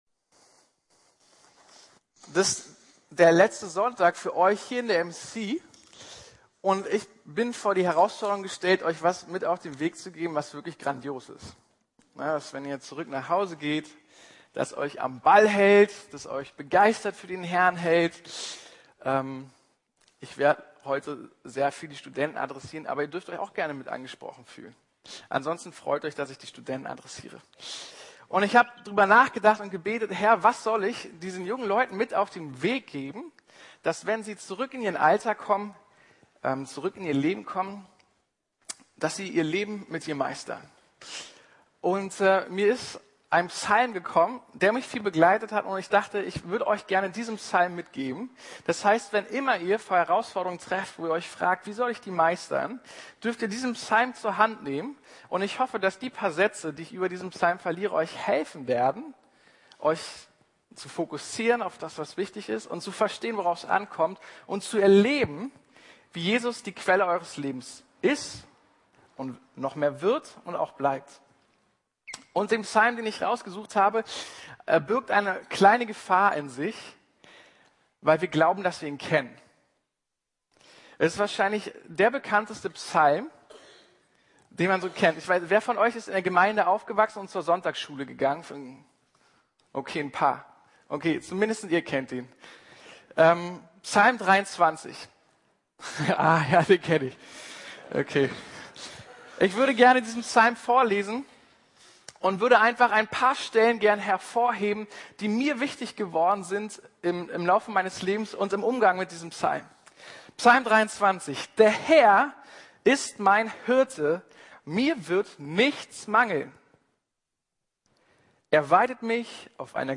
Ich fürchte nichts (MC Abschlussgottesdienst) ~ Predigten der LUKAS GEMEINDE Podcast